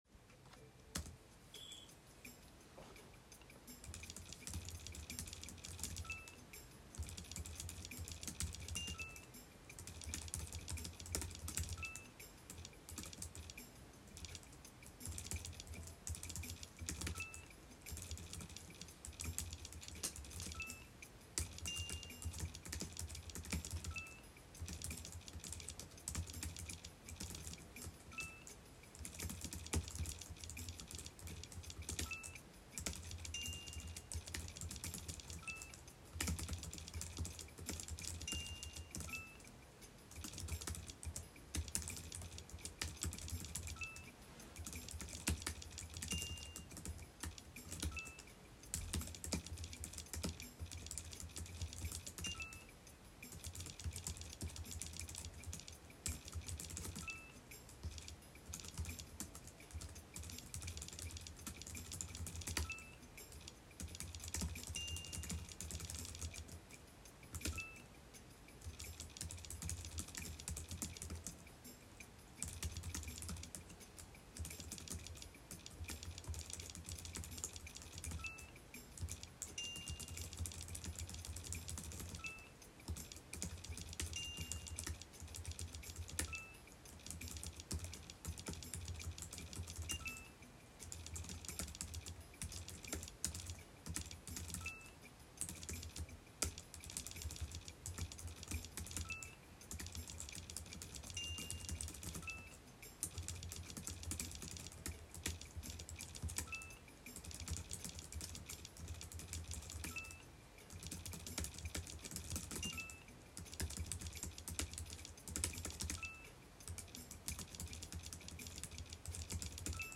一発勝負ということで、無駄に音声を収録しました（笑）
キーボードのタッチ音がどんなものか、参考になれば幸いです。
ちょっと久しぶりで気合が入った分、無駄に強く打ちすぎてうるさいです（笑）